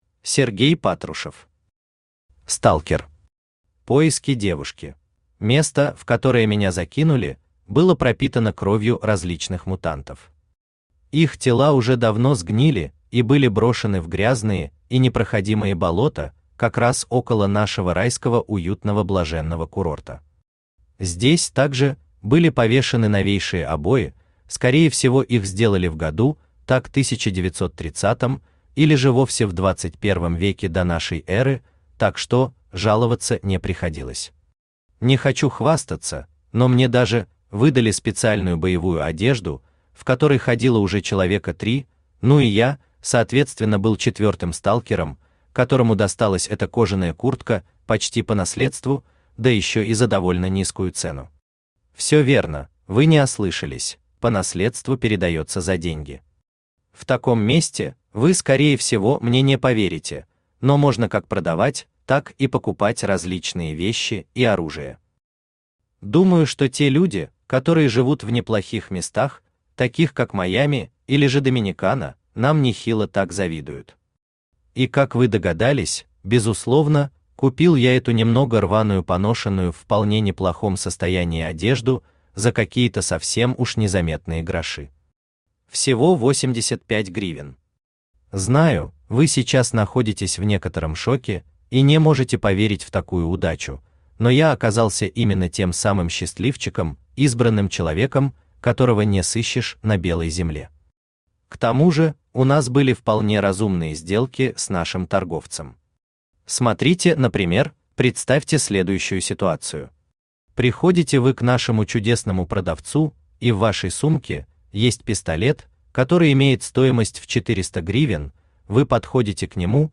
Аудиокнига Сталкер. Поиски девушки | Библиотека аудиокниг
Поиски девушки Автор Сергей Патрушев Читает аудиокнигу Авточтец ЛитРес.